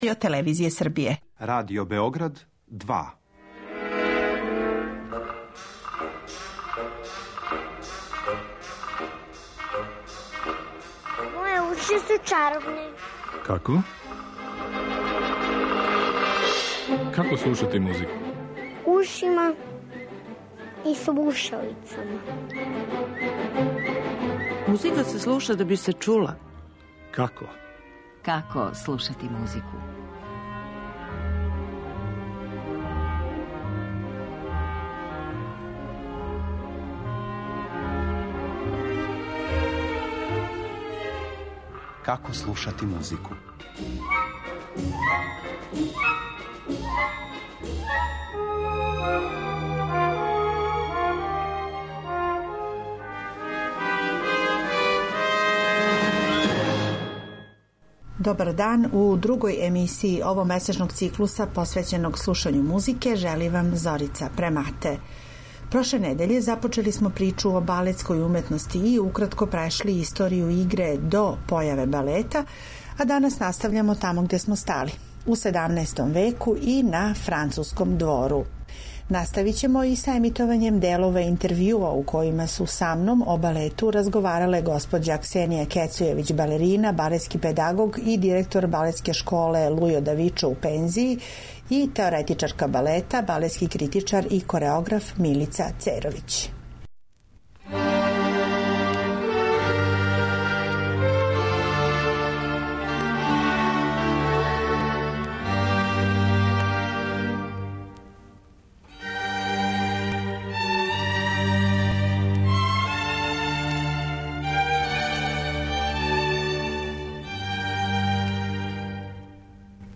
Чућете и одломке из балета насталих у том периоду, а из пера Жана Батисте Лилија, Жана Филипа Рамоа и самог краља Луја XIII.